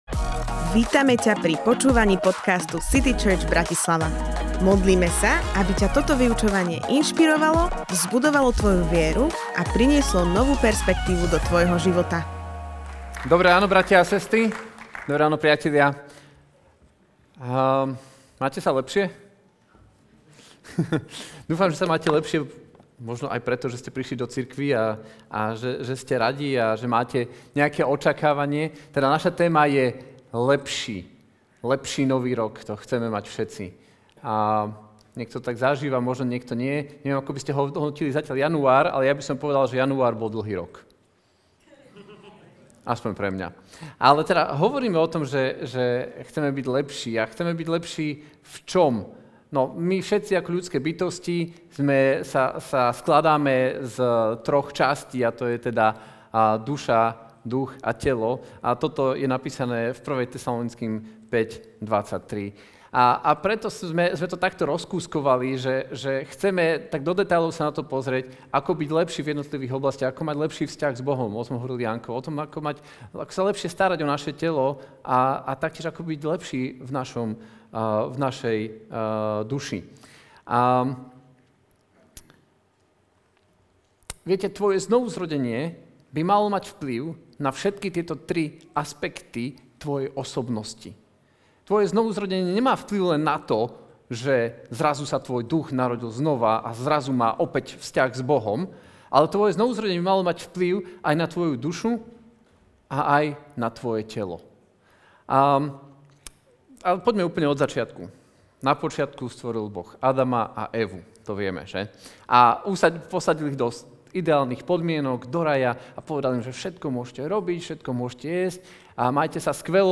LEPŠÍ v duši Kázeň týždňa Zo série kázní